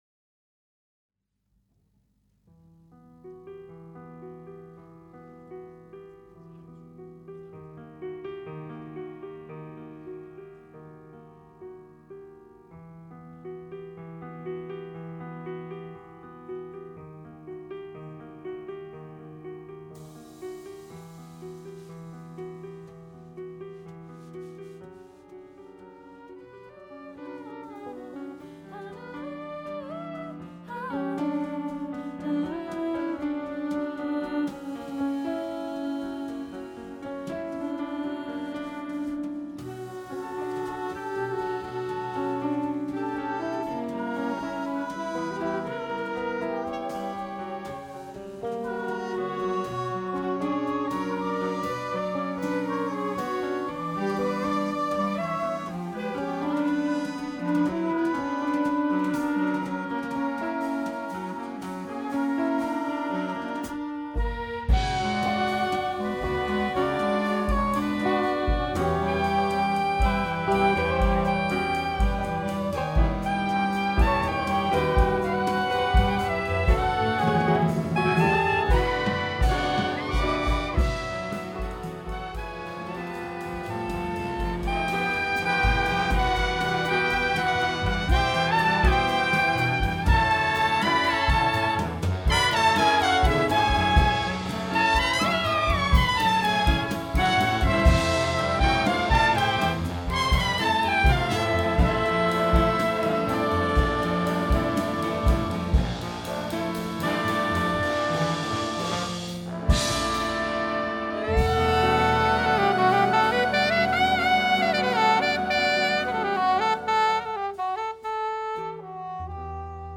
für zeitgenössisches Jazzorchester